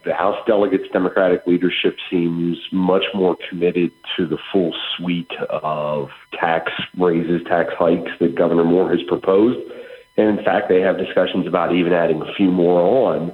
House Minority Leader Jason Buckel spoke with WBAL Radio and said it seems nothing is off the table for the Democrats…